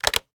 taxi_hangup.ogg